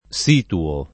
situare
vai all'elenco alfabetico delle voci ingrandisci il carattere 100% rimpicciolisci il carattere stampa invia tramite posta elettronica codividi su Facebook situare [ S itu- # re ] v.; situo [ S& tuo ] — pres. 1a pl. situiamo [ S itu L# mo ]